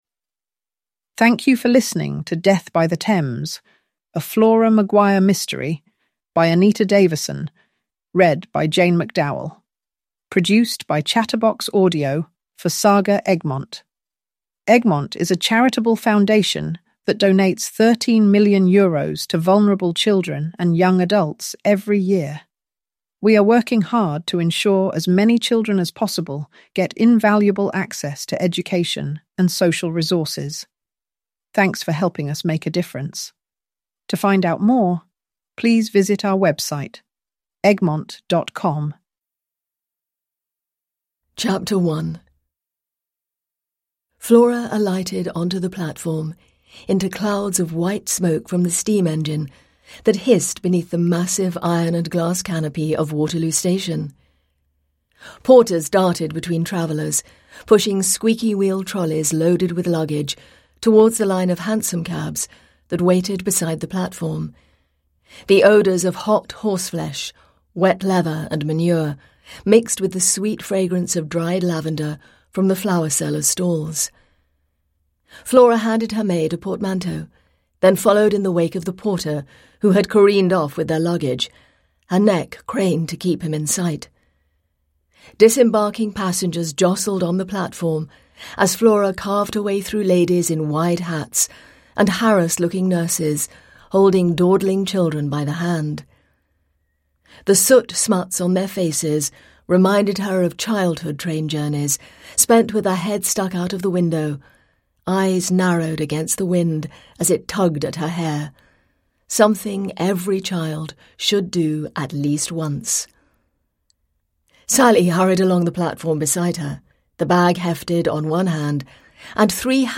Death of a Suffragette: a fast-paced historical cosy mystery series / Ljudbok